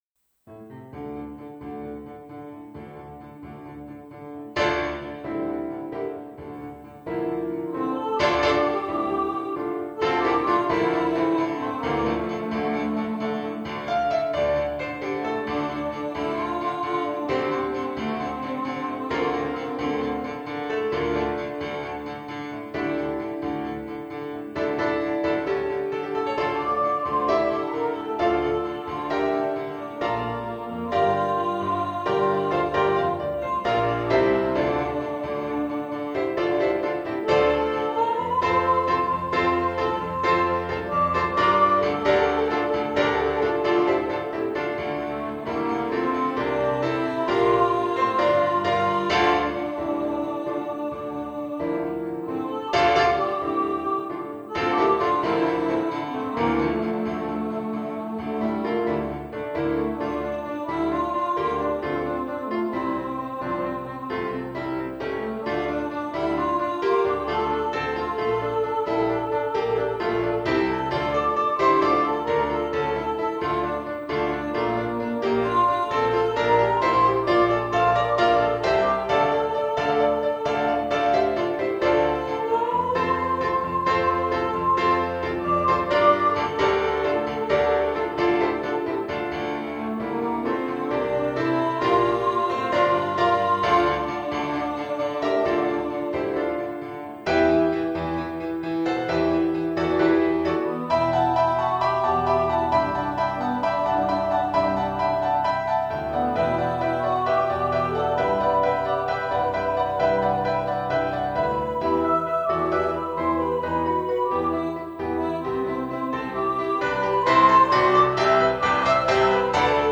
Ghost-Riders-In-The-Sky-Soprano.mp3